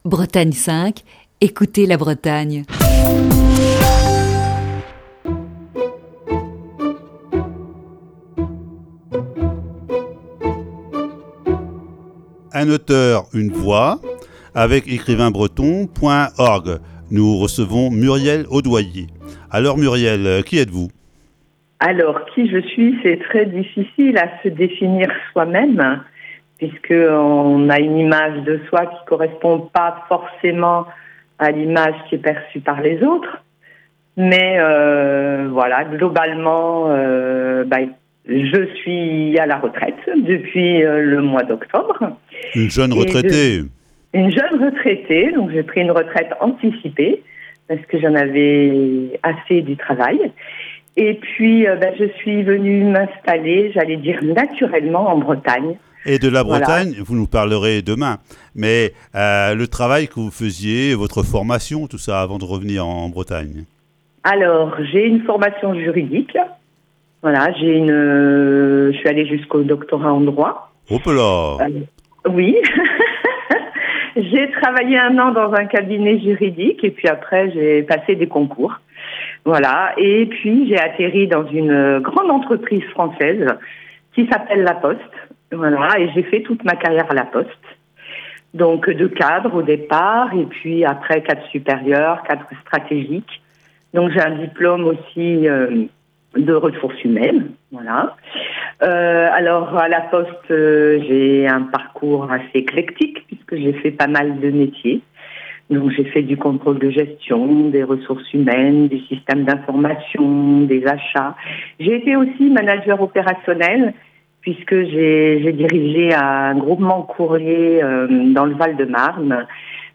Voici, ce lundi, la première partie de cet entretien.